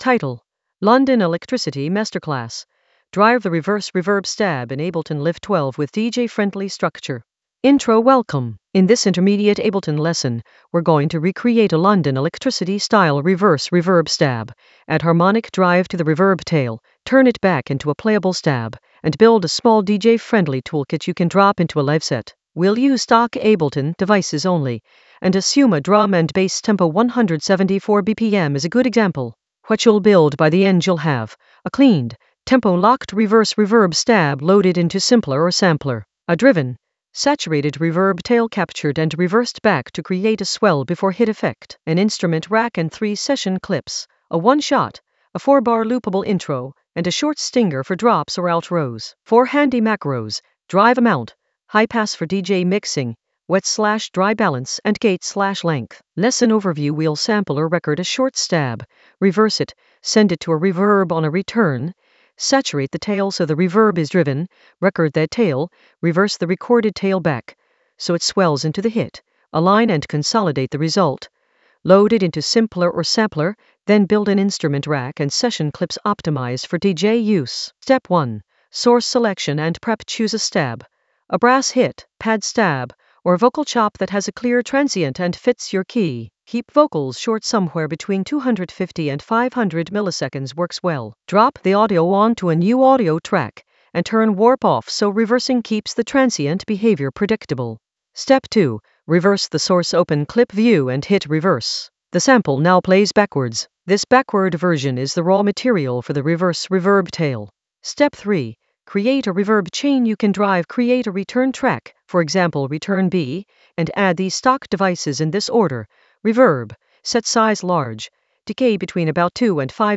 Narrated lesson audio
The voice track includes the tutorial plus extra teacher commentary.
An AI-generated intermediate Ableton lesson focused on London Elektricity masterclass: drive the reverse reverb stab in Ableton Live 12 with DJ-friendly structure in the Sampling area of drum and bass production.